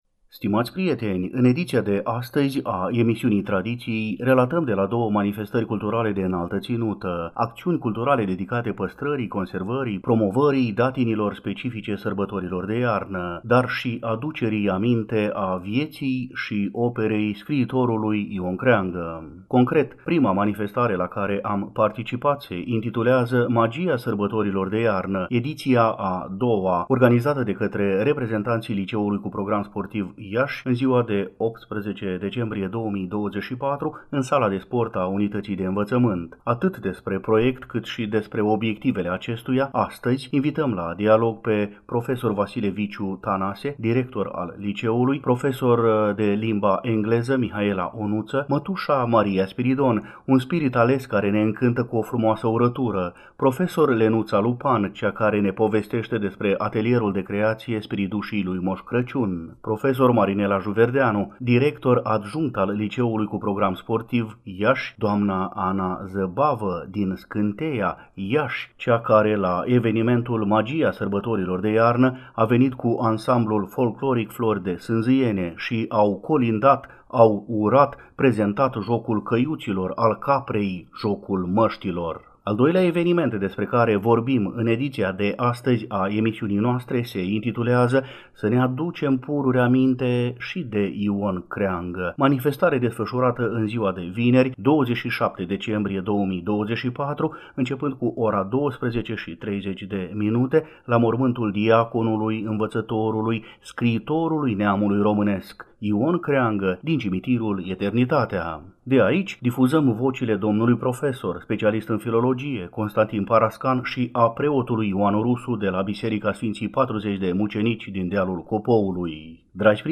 Concret, prima manifestare la care am participat se intitulează „Magia sărbătorilor de iarnă”, ediția a II-a, organizată de către reprezentanții Liceului cu Program Sportiv Iași, în ziua de 18 decembrie 2024, în sala de sport a unității de învățământ.